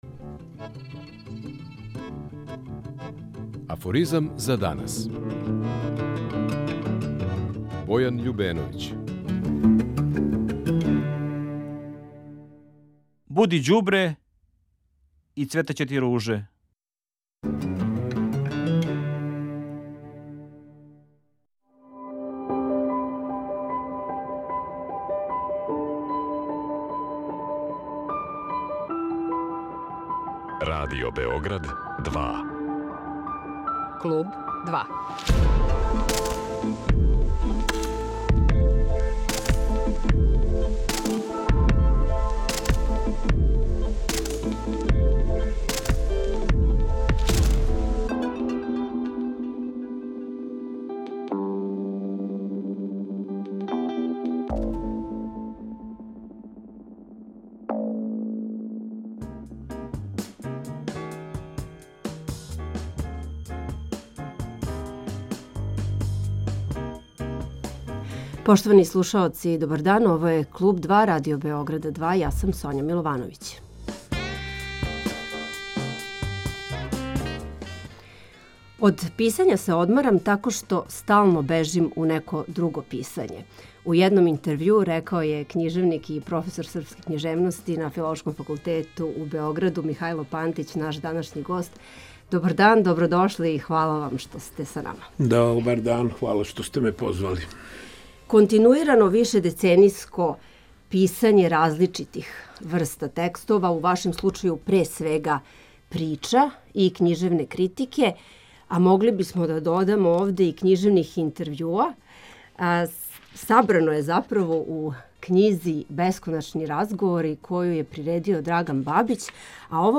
Гост у Клубу 2 је писац, књижевни критичар, професор Филолошког факултета у Београду Михајло Пантић.